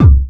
Kick_14_b.wav